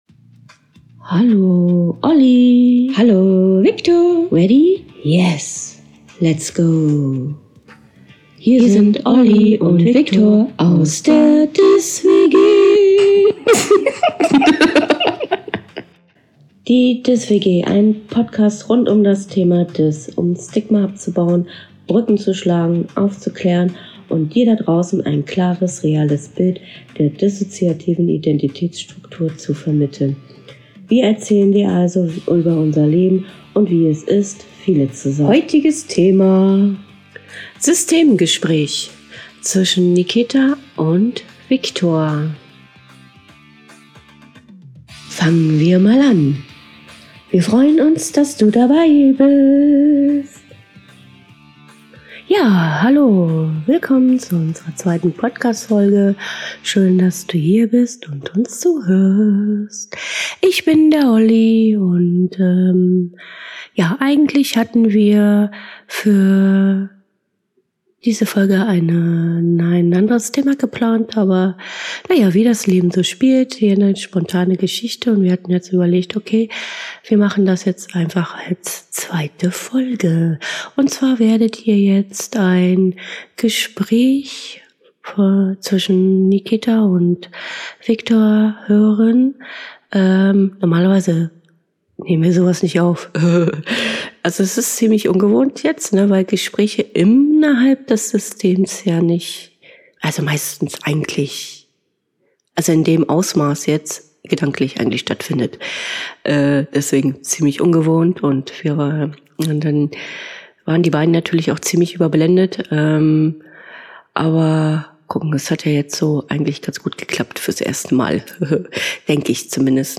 Es war also sehr ungewohnt für die beiden und sie waren recht überblendet. Aber ich denke man kann dem Gespräch dennoch folgen.